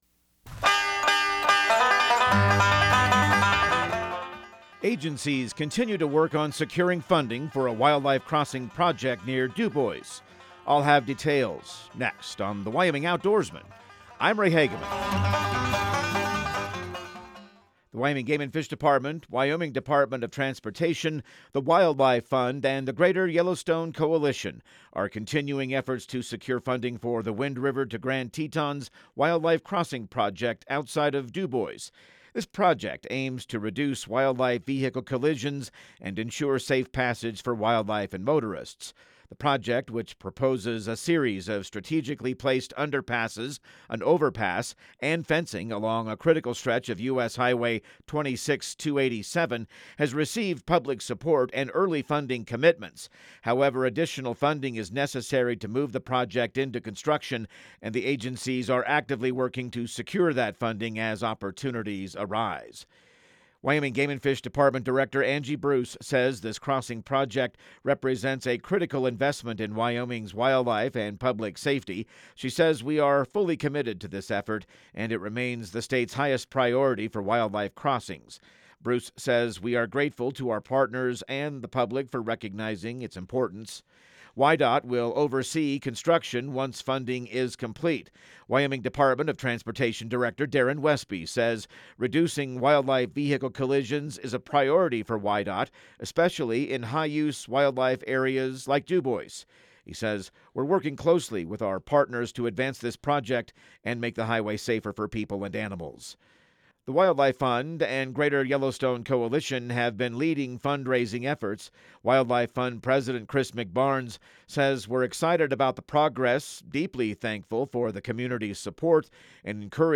Radio news | Week of May 26